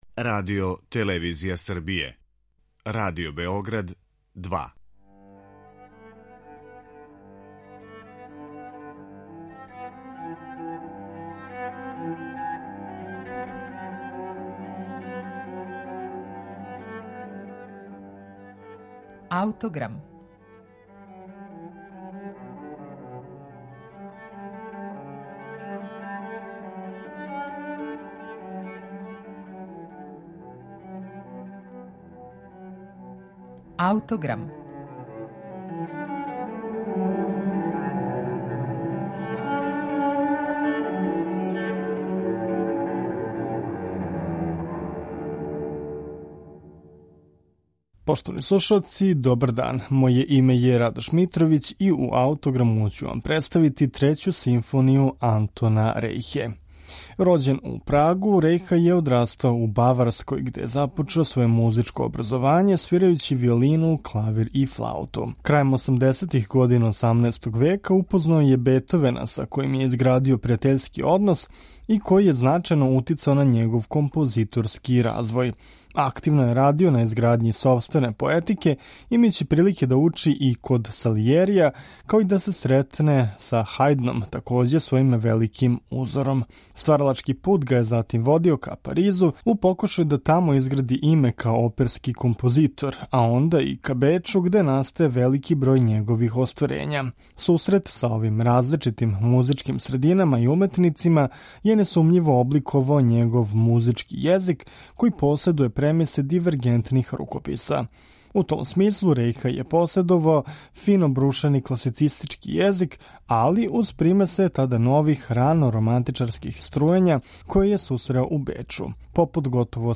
Ми ћемо слушати трећу симфонију у извођењу оркестра академије Бетовен.